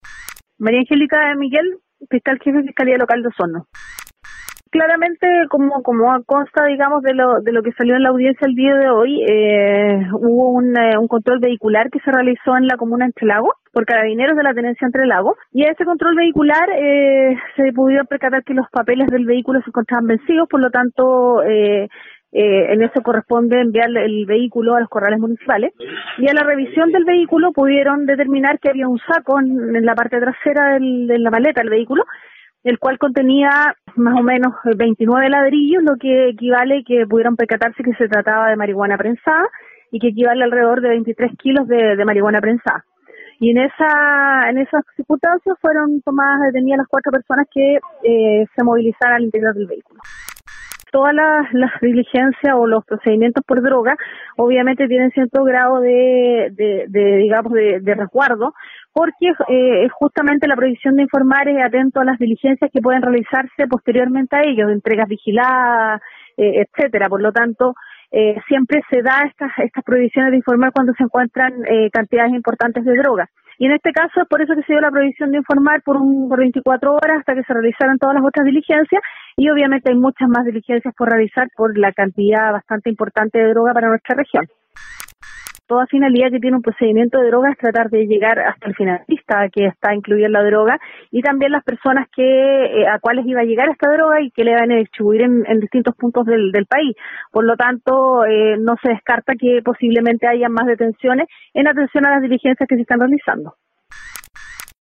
Fiscal de Angélica De Miguel entregó antecedentes respecto a la investigación tras la detención de 4 personas (3 hombres y una mujer) quienes fueron sorprendidos transportando 23 kilos de cannabis sativa del tipo prensada en la ruta internacional 215